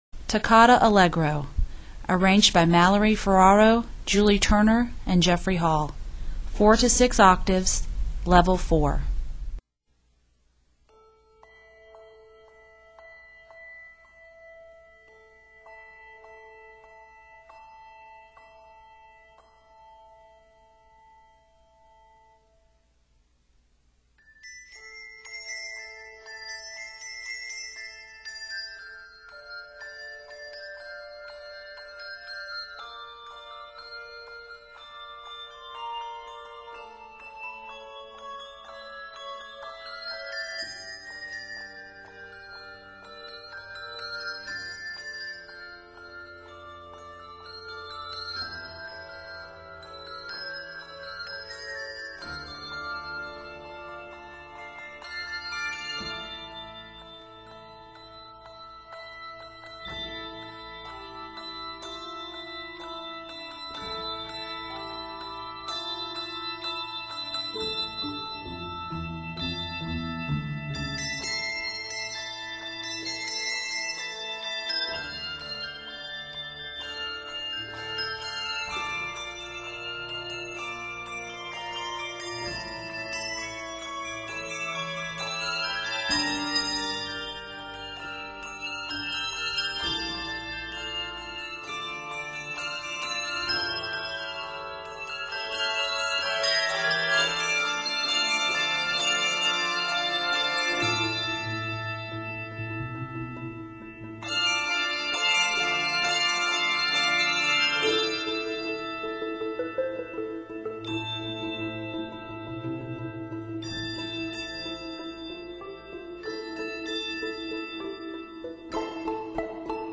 Scored in a minor, it is 98 measures in length.